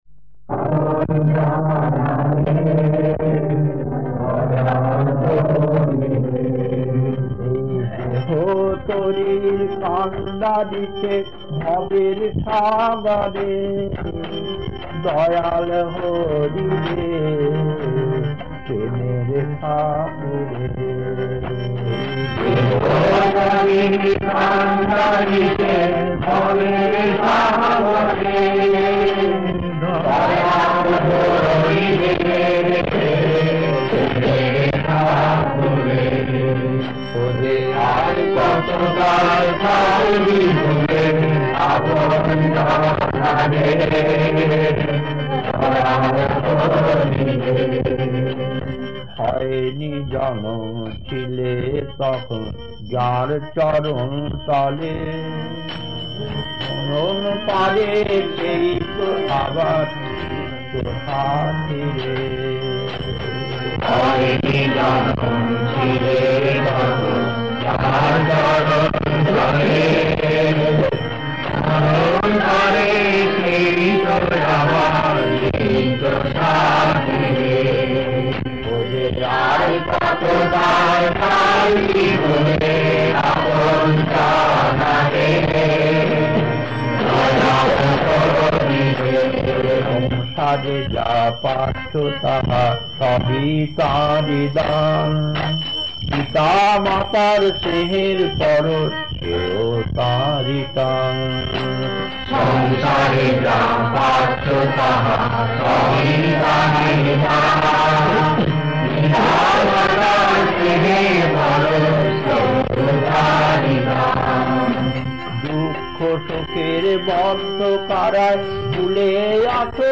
Kirtan B12-1 Puri, 1979, 66 minutes 1.